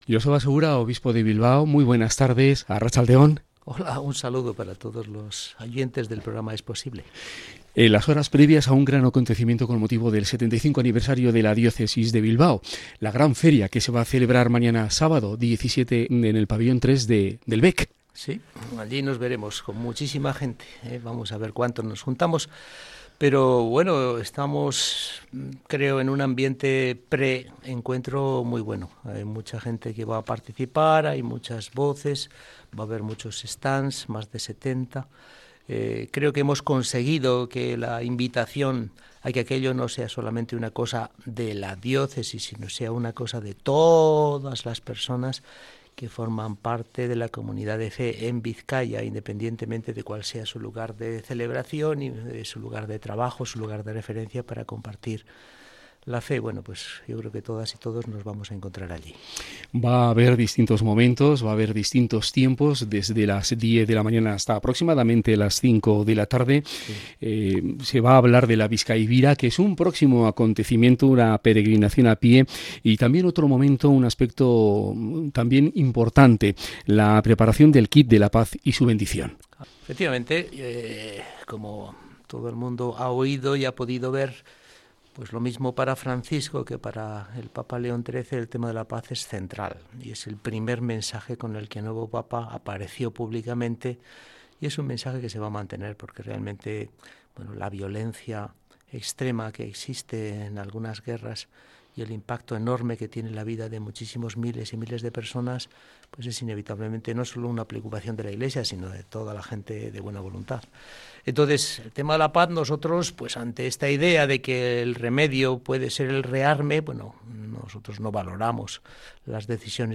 Joseba Segura, Obispo de Bilbao, en Radio Popular - Herri Irratia / Radio Popular - Herri Irratia